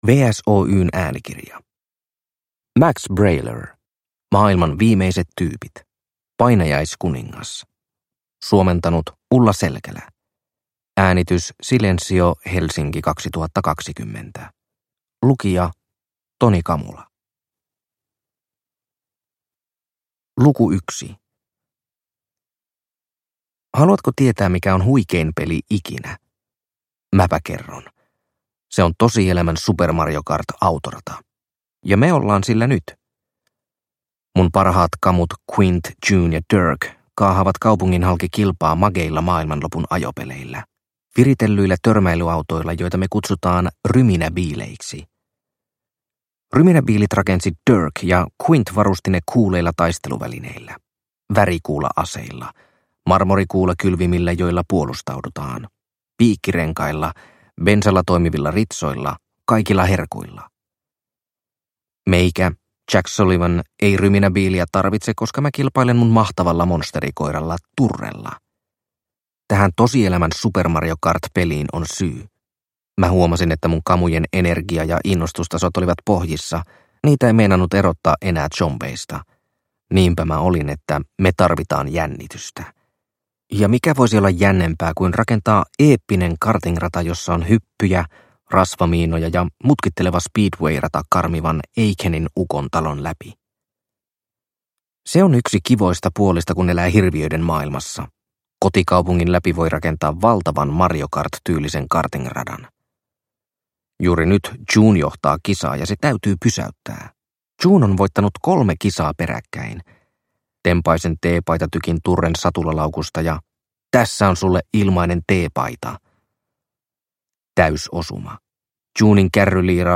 Maailman viimeiset tyypit - Painajaiskuningas – Ljudbok – Laddas ner